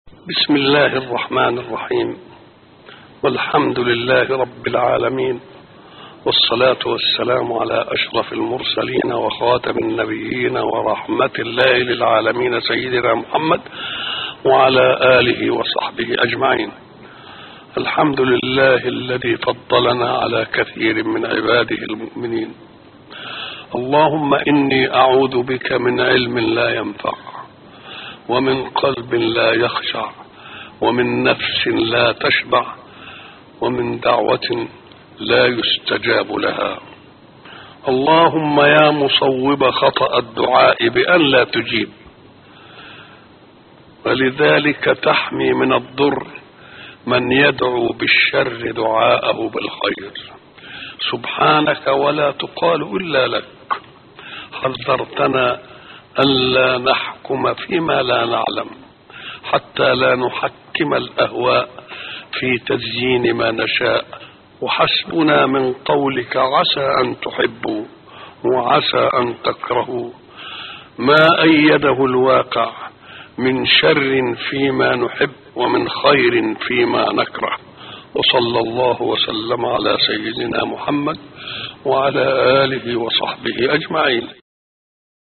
دعاء خاشع ومؤثر للشيخ محمد متولي الشعراوي.
تسجيل لدعاء خاشع ومميز للشيخ الجليل محمد متولي الشعراوي.